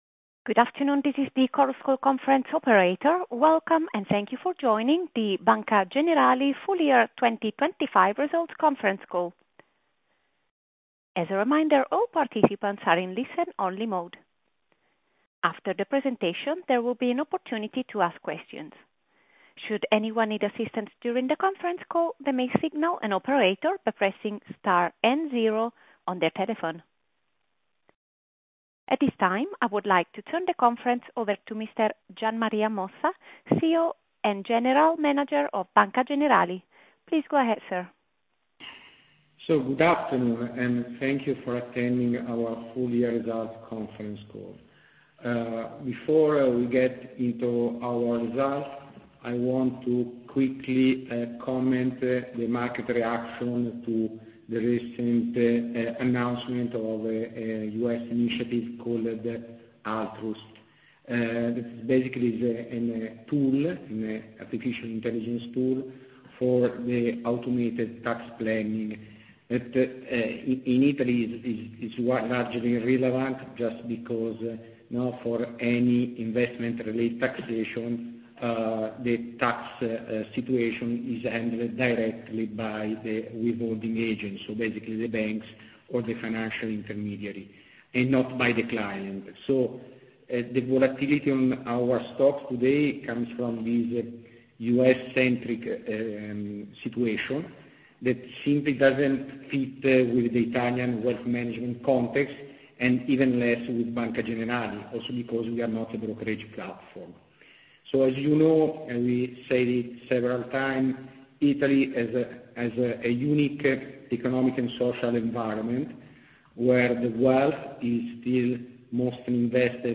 Risultati Preliminari 2025 - Audio Conf. Call